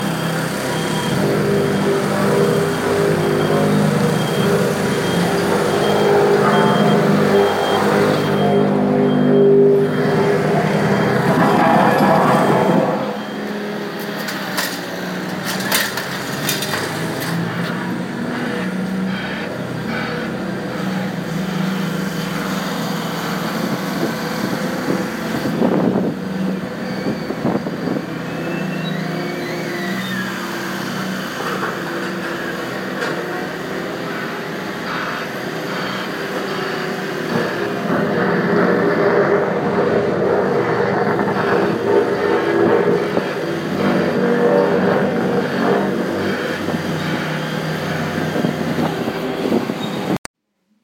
road surfacing